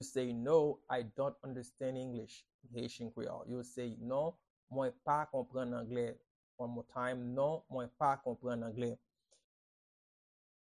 Pronunciation and Transcript:
No-I-dont-understand-English-in-Haitian-Creole-Non-mwen-pa-konprann-angle-pronunciation-by-a-Haitian-teacher.mp3